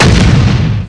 EXPLO8.WAV